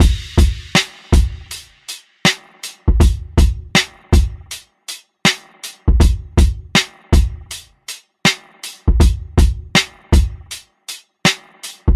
DRUM LOOPS
(160 BPM – Fm)